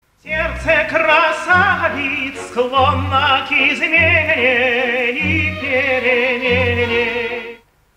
Кто поёт?
Правильный ответ: Иван Семёнович Козловский поёт арию Герцога